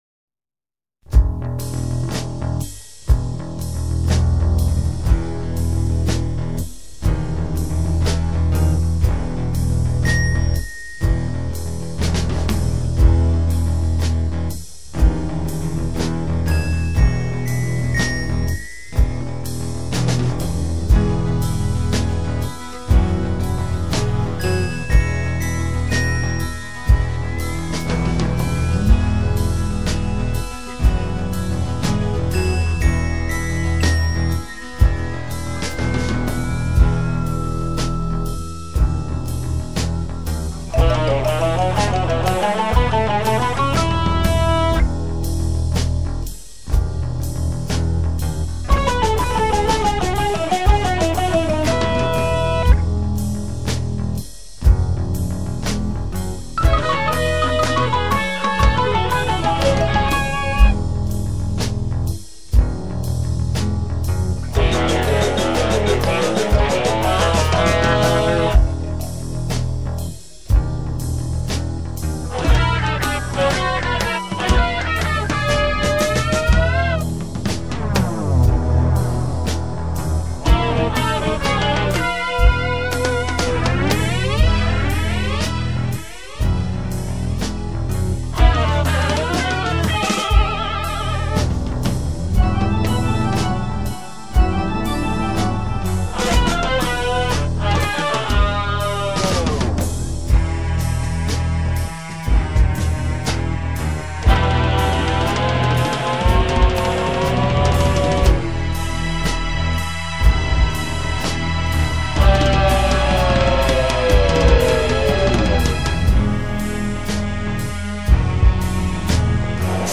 An instrumental
I play bass, drums, and keyboards.
6-part guitar feedback swell